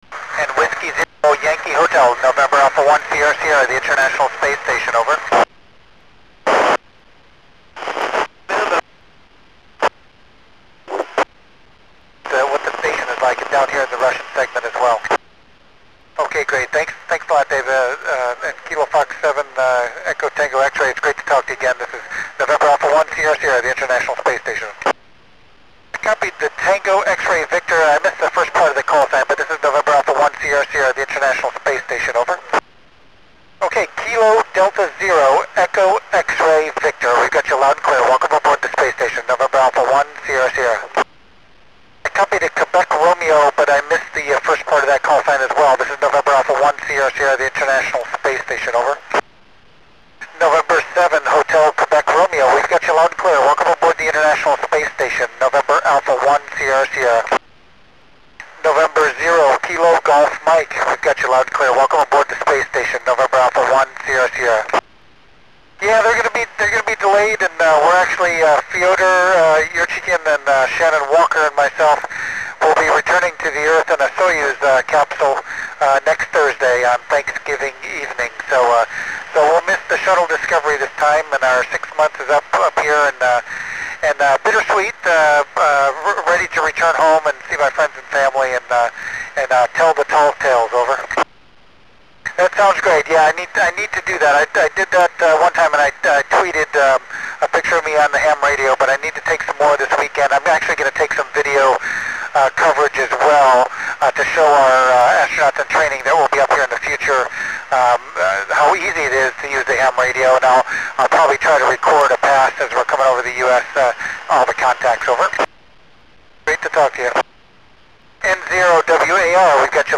Col Doug Wheelock talking about returing home and about taking video to train others to use radio